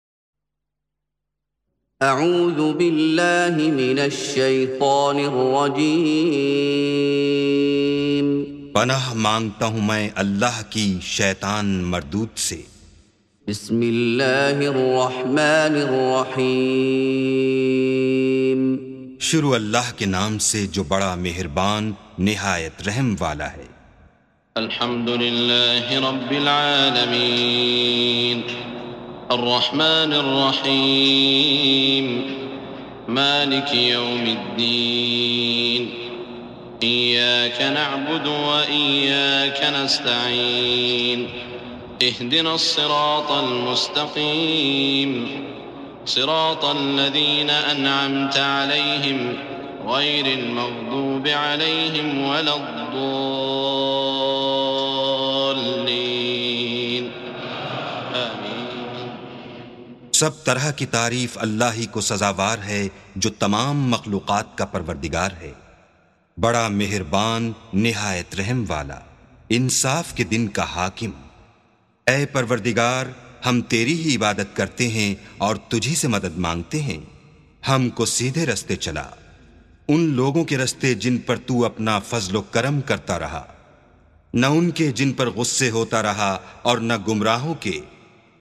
سُورَةُ الفَاتِحَةِ بصوت الشيخ السديس والشريم مترجم إلى الاردو